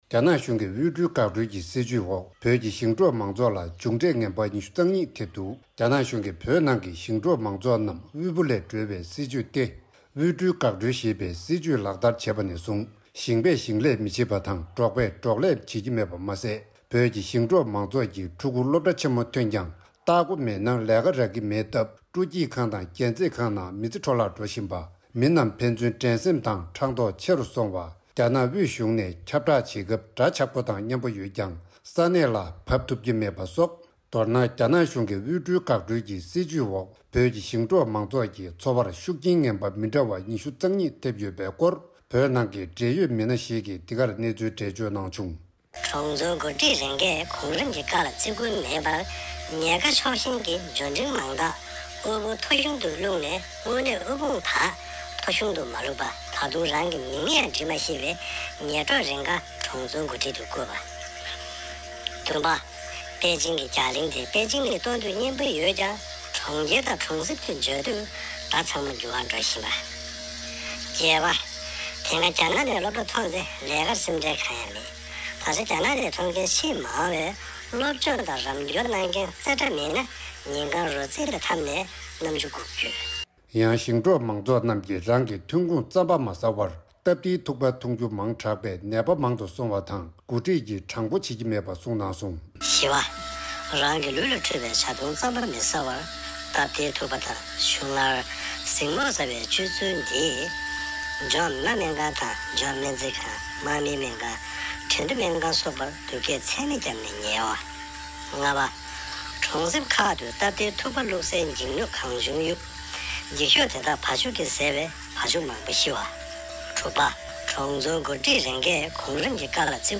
རྒྱ་ནག་གཞུང་གིས་བོད་ནང་གི་ཞིང་འབྲོག་མང་ཚོགས་རྣམས་དབུལ་པོ་ལས་སྒྲོལ་བའི་སྲིད་ཇུས་ཏེ་དབུལ་སྒྲོལ་འགག་སྒྲོལ་ཞེས་པའི་སྲིད་ཇུས་ལག་བསྟར་བྱས་པ་ནས་བཟུང་ཞིང་པས་ཞིང་ལས་མི་བྱེད་པ་དང་འབྲོག་པས་འགྲོག་ལས་བྱེད་ཀྱི་མེད་པ་མ་ཟད་བོད་ཀྱི་ཞིང་འབྲོག་མང་ཚོགས་ཀྱི་ཕྲུ་གུ་སློབ་གྲྭ་ཆེན་མོ་ཐོན་ཀྱང་སྟག་སྒོ་ཡག་པོ་མེད་ན་ལས་ཀ་རག་གི་མེད་སྟབས་སྤྲོ་སྐྱིད་ཁང་དང་རྒྱན་རྩེད་ཁང་ནང་མི་ཚེ་འཕྲོ་བརླག་འགྲོ་བཞིན་པ། མི་རྣམས་རྣམས་ཕན་ཚུན་འགྲན་སེམས་དང་ཕྲག་དོག་ཆེ་རུང་སོང་བ། རྒྱ་ནག་དབུས་གཞུང་ནས་ཁྱབ་བསྒྲགས་བྱེད་སྐབས་འདྲ་ཆགས་པོ་དང་སྙན་པོ་ཡོད་ཀྱང་གནས་ལ་བབ་ཐུབ་ཀྱི་མེད་པ་སོགས། མདོར་ན་དབུལ་སྒྲོལ་འགག་སྒྲོལ་གྱི་སྲིད་ཇུས་འོག་བོད་ཀྱི་ཞིང་འབྲོག་མང་ཚོགས་ཀྱི་འཚོ་བར་ཤུགས་རྐྱེན་ངན་པ་མི་འདྲ་བ་༢༢ ཐེབས་ཡོད་པའི་སྐོར་བོད་ནང་གི་འབྲེལ་ཡོད་མི་སྣ་ཞིག་གིས་འདི་གར་གནས་ཚུལ་འགྲེལ་བརྗོད་གནང་དོན་སྒྲ།། ཡང་ཞིང་འབྲོག་མང་ཚོགས་ཀྱིས་རང་གི་ཐོན་ཁུངས་རྩམ་པ་མ་ཟ་བར་སྟབས་བདེའི་ཐུག་པ་འཐུ་རྒྱུ་མང་དྲག་པས་ནད་པ་མང་དུ་སོང་བ་དང་འགོ་ཁྲིད་ཀྱིས་དྲང་པོ་བྱེད་ཀྱི་མེད་པ་གསུངས་སོང་།